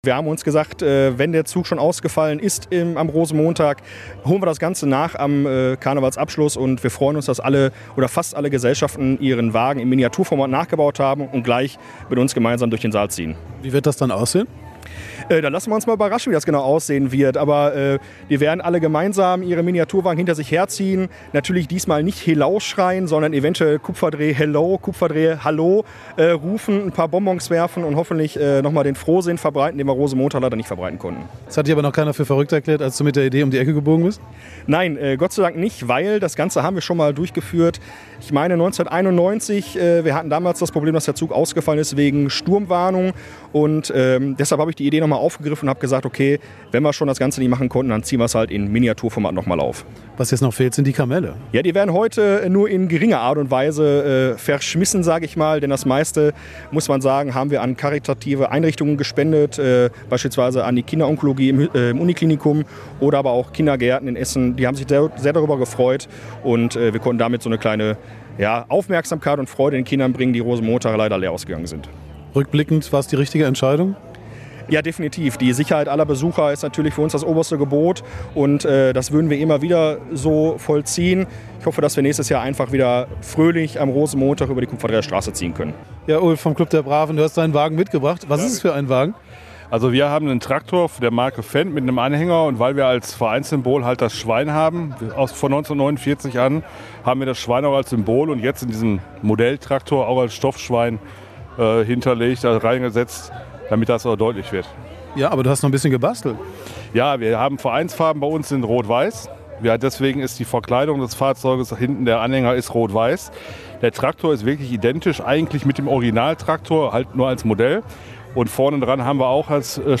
Riesen Stimmung in Essen beim Mini-Karnevalsumzug - Radio Essen